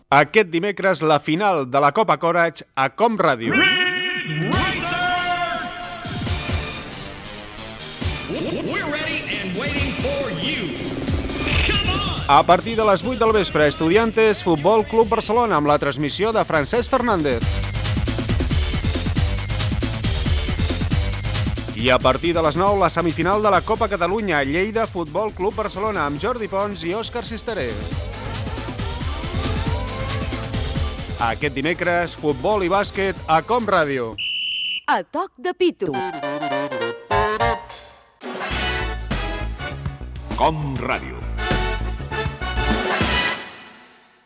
Promoció de la semifinal de la Copa Catalunya de futbol masculí i de la Copa Korać de bàsquet
Esportiu